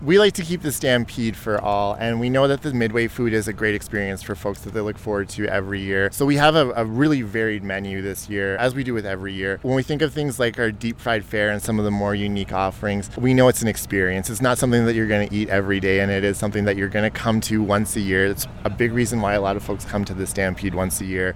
To help get the excitement going Calgary Stampede officials welcome Media to a taste testing of some creations for the upcoming Midway.